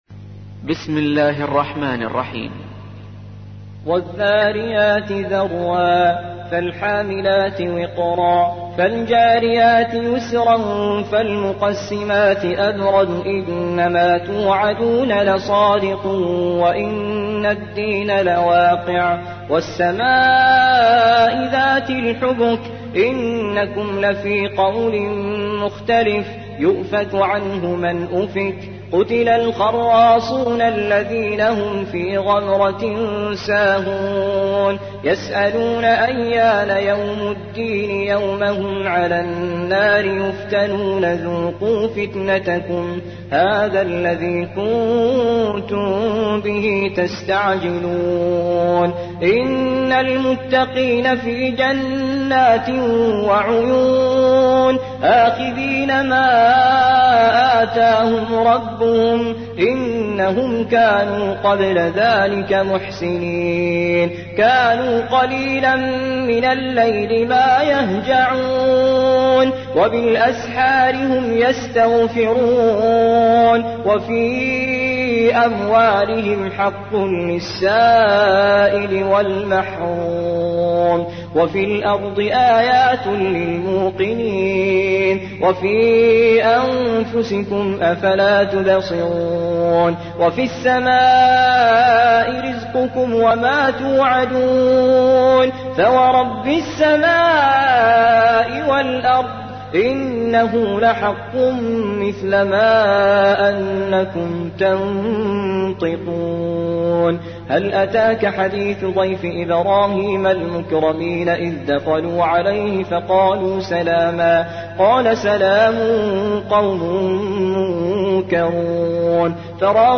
تحميل : 51. سورة الذاريات / القارئ توفيق الصايغ / القرآن الكريم / موقع يا حسين